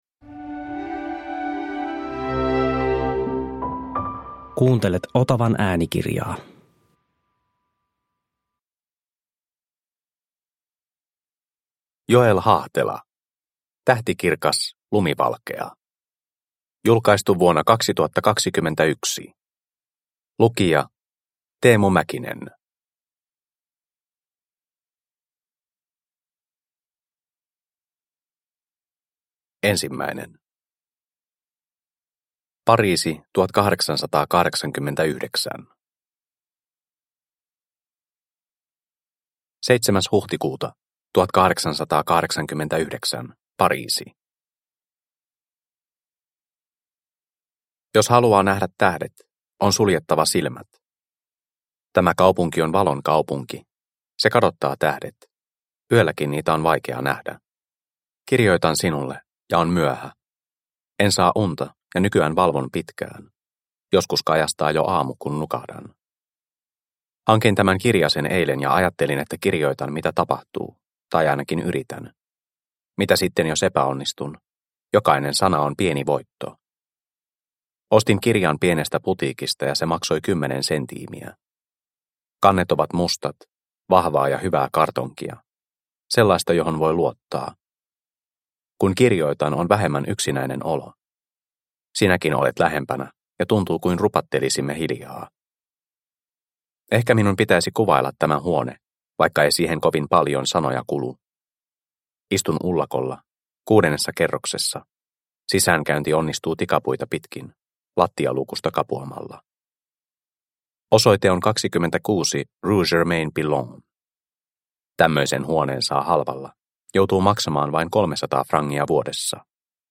Tähtikirkas, lumivalkea – Ljudbok – Laddas ner